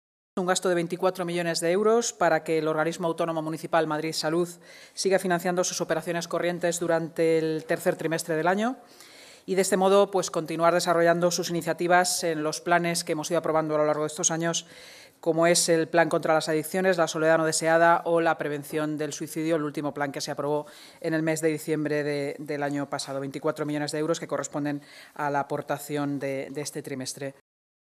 Nueva ventana:Declaraciones de la portavoz municipal, y delegada de Seguridad y Emergencias en funciones, Inmaculada Sanz